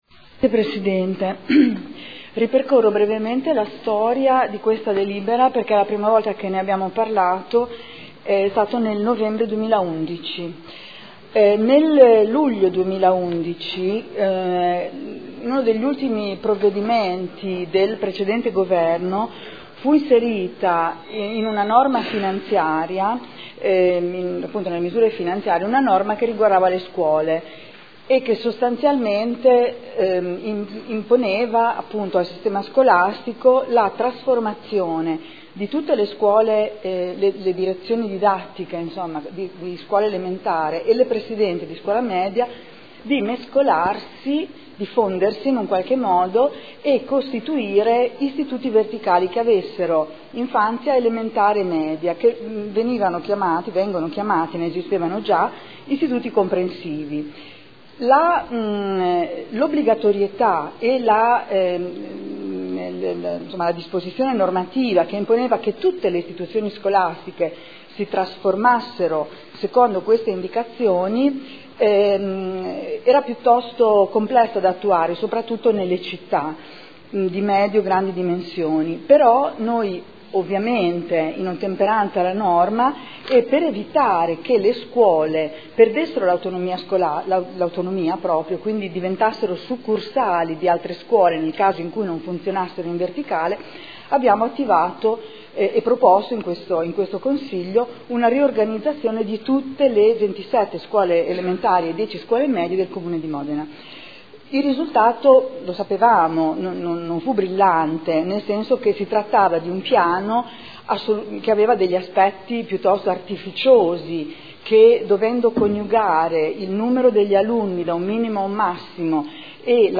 Seduta del 21/11/2013 Proposta di deliberazione: Modifica della riorganizzazione rete Istituzioni scolastiche approvata con deliberazione del Consiglio comunale n. 63/2012